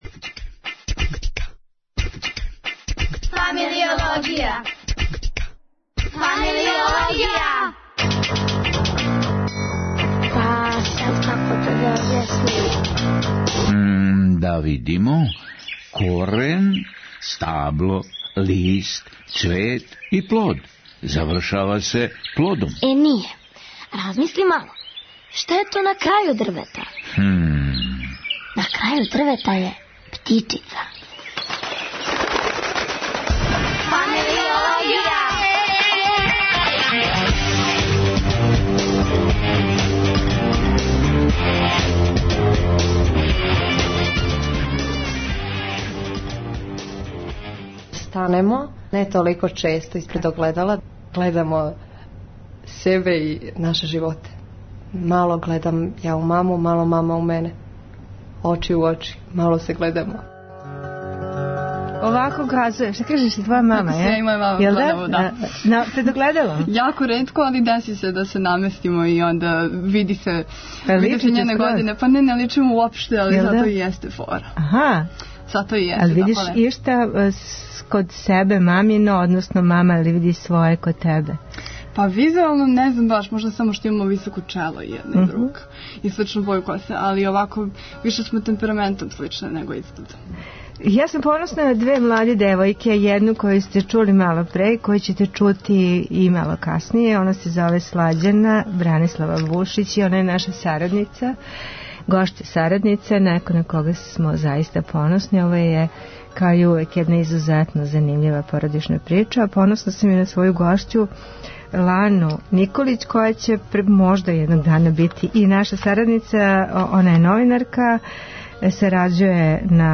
Имамо укључење из Тршића.